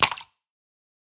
Здесь вы найдете как знакомые «хрустящие» эффекты корзины, так и более современные варианты.
Звуковое оповещение при удалении файла в интерфейсе